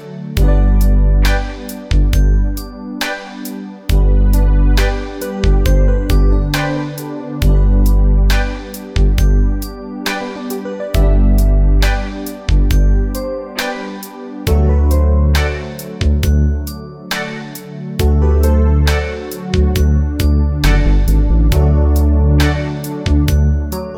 no Backing Vocals Reggae 5:25 Buy £1.50